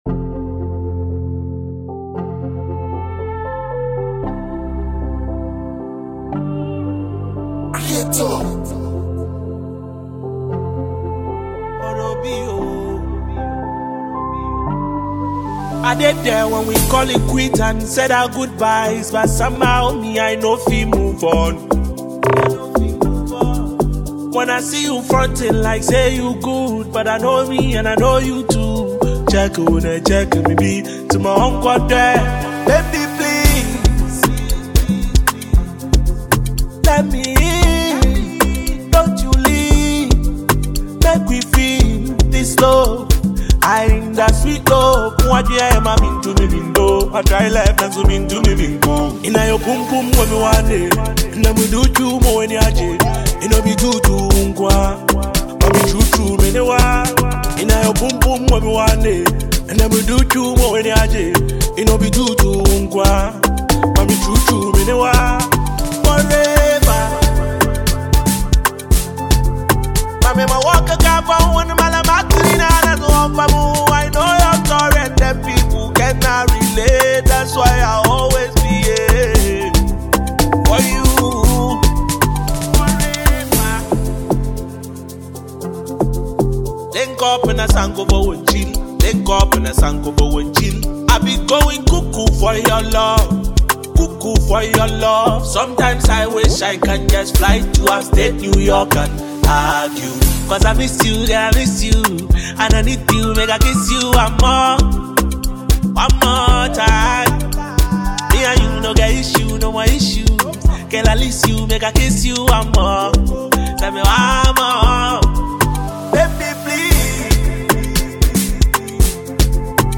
a US-based Ghanaian rapper